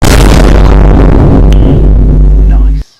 Better Bear Attacking Sound Button - Free Download & Play